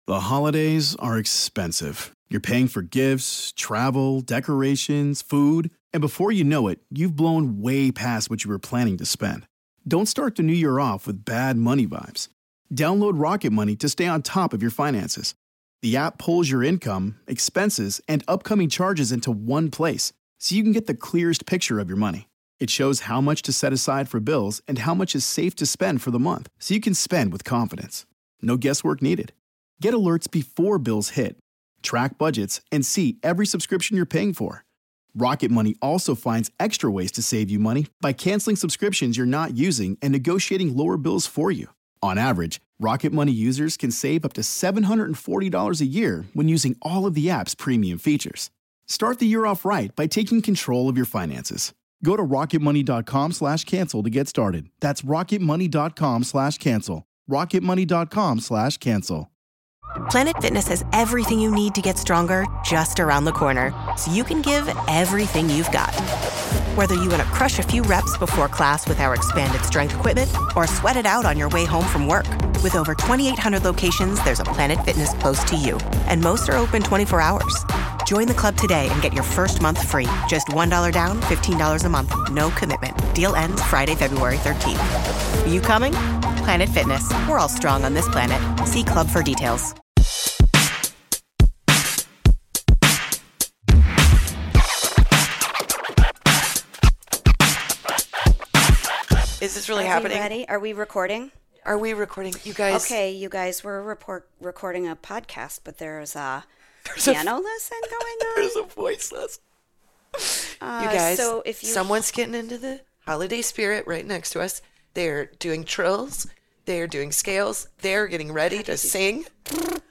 Ladies, listen along as we try to record this episode and avoid an aggressive singer, a drug deal and a landing strip.
-- ABOUT US: This hilarious comedy podcast about motherhood is for moms by moms talking all about being a mom.